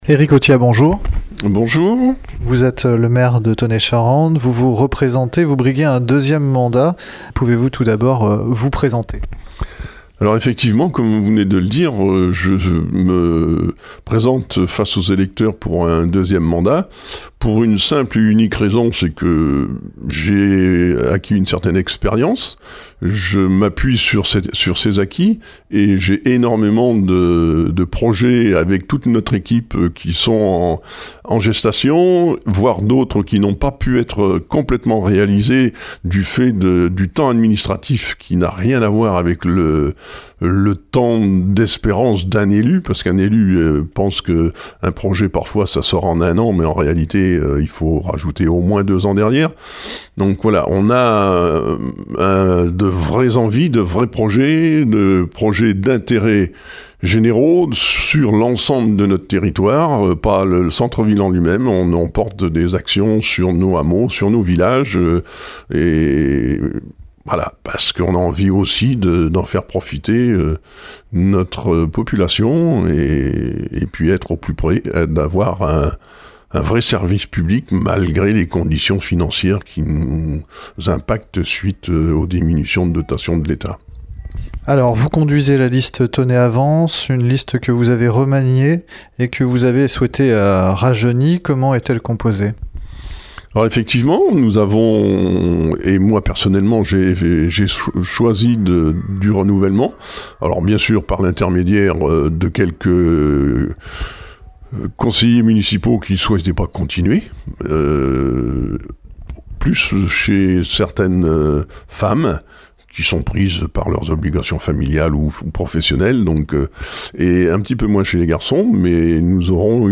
Ecoutez l’interview d’Eric Authiat – Tonnay avance :